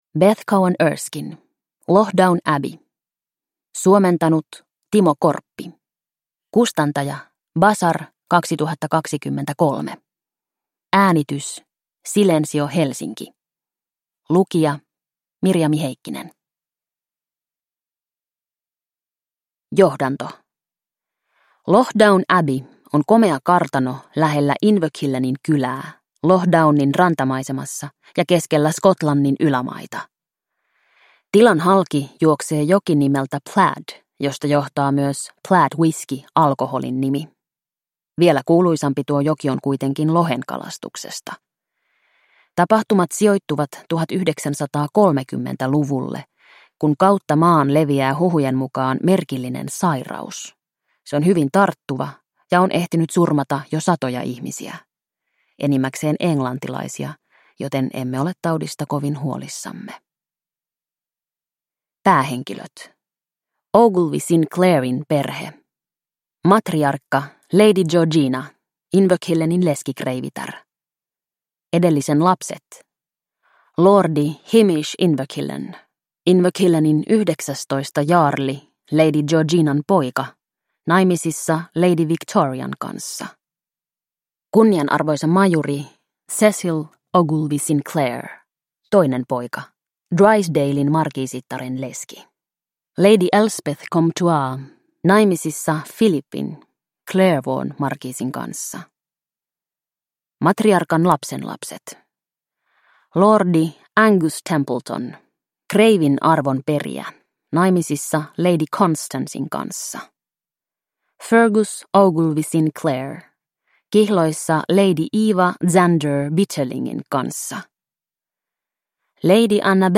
Loch Down Abbey – Ljudbok – Laddas ner